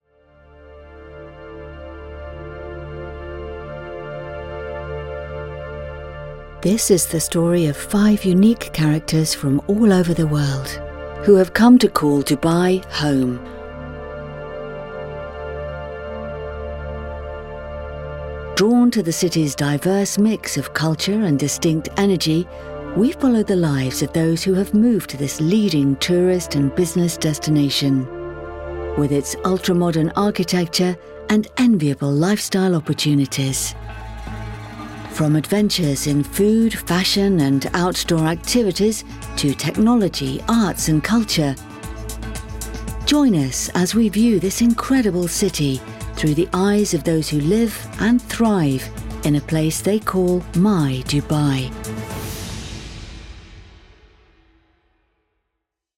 English RP voiceover. 14 years experience with top brand clients. Own, professional level studio near Oxford.
South London, English RP, English classy.
Documentary